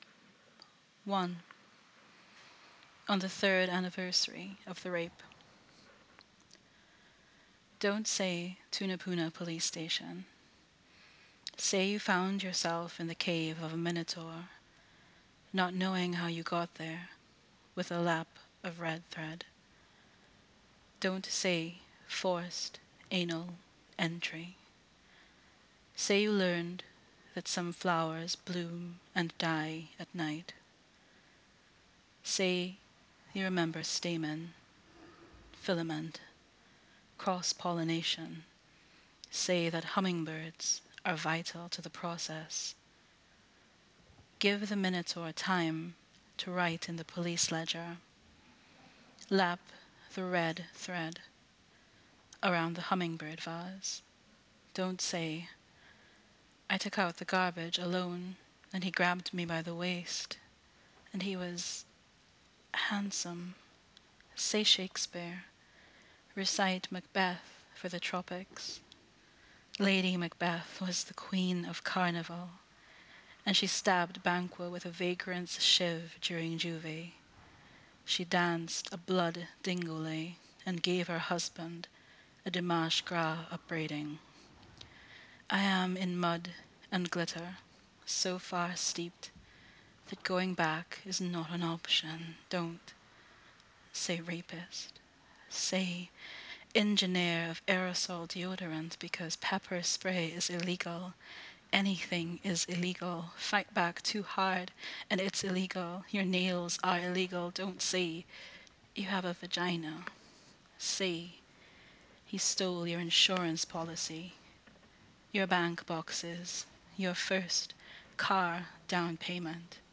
Letterpress-printed books with recorded reading